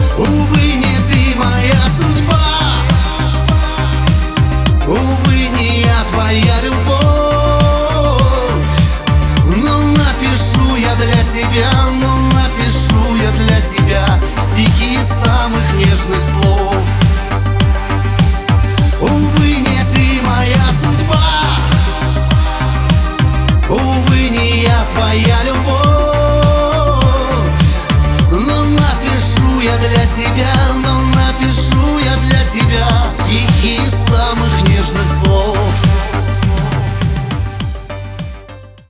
• Качество: 320, Stereo
русский шансон